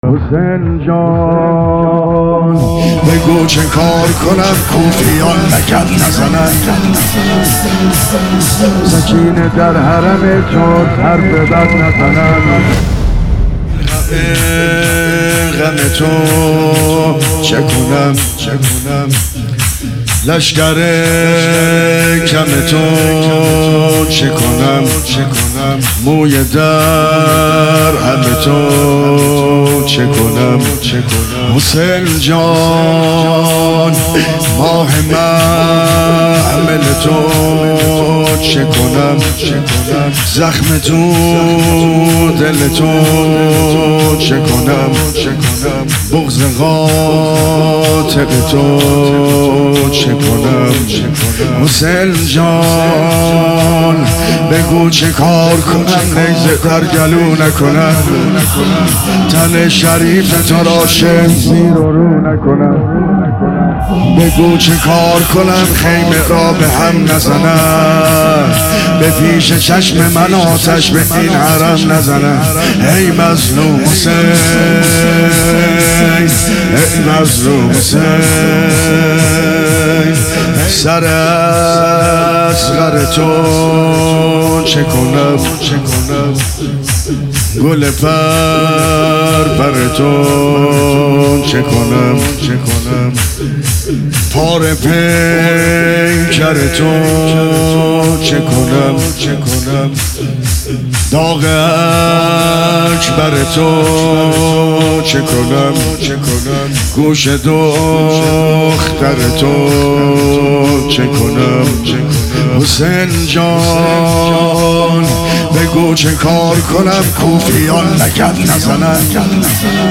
دانلود صوتی و ویدئویی بگو چه کار کنم عبدالرضا هلالی به همراه متن تن بی سرتو چه کنم محرم 1400 مداحی شور امام حسین علیه السلام - پلان3
حاج عبد الرضا هلالی | محرم 1400 | حسینیه کربلا تهران | پلان 3